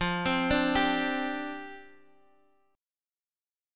F62 chord {x x 3 5 3 3} chord